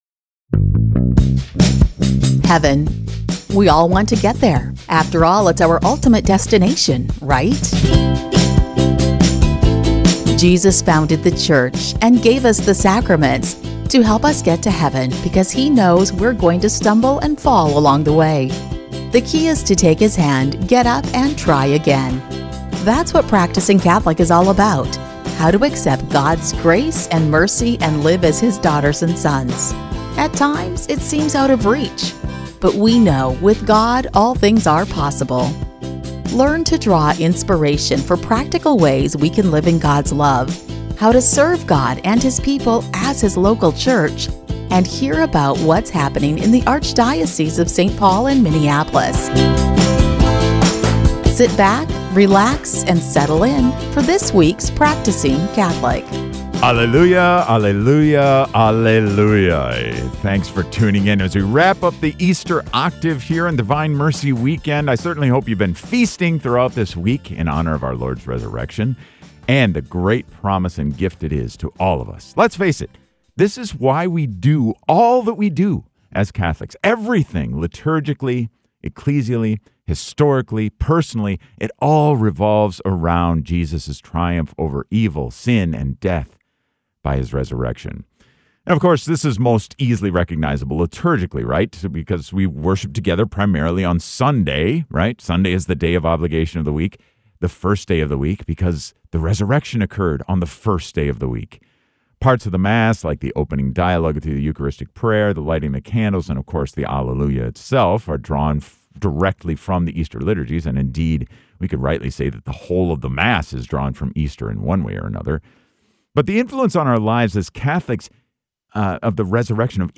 Archbishop Hebda is back this week for another Bishops’ Segment! We’ll take a look at some Easter traditions from Archbishop Hebda’s childhood, as well as reflect on Divine Mercy Sunday.
We’ll even hear a sample from a few special guests from the school.